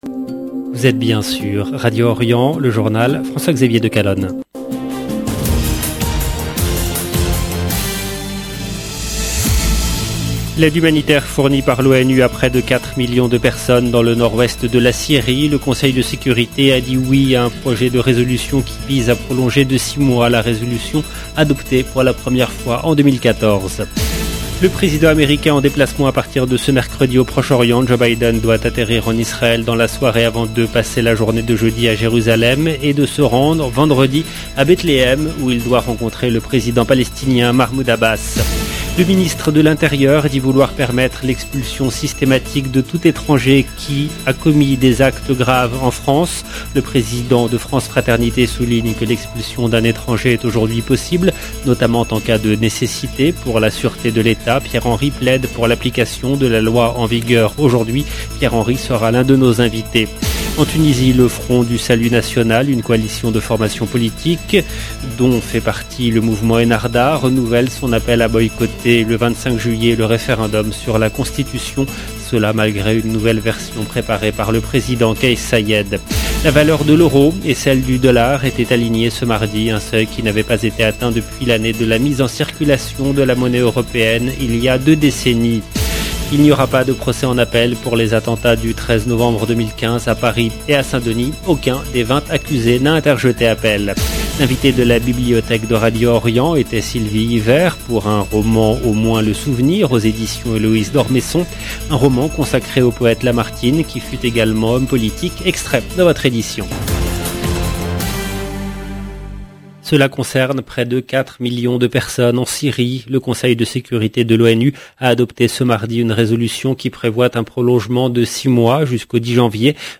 LE JOURNAL EN LANGUE FRANCAISE DU SOIR DU 12/07/22